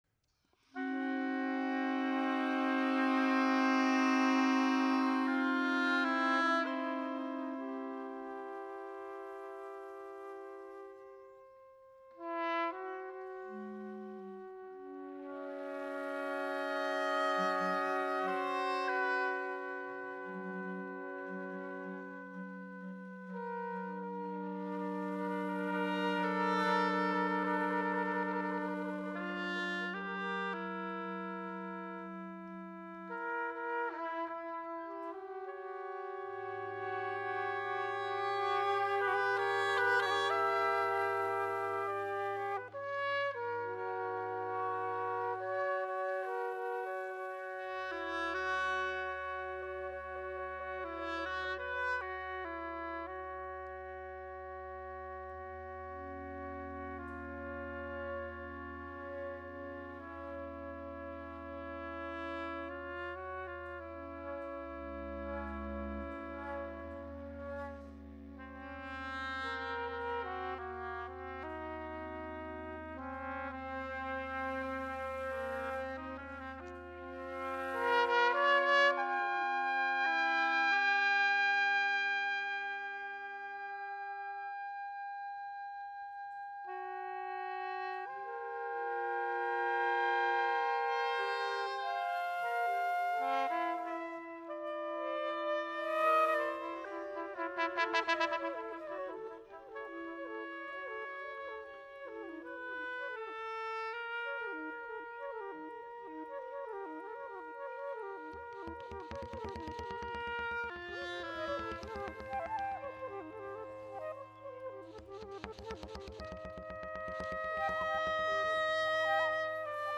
au th��tre du lierre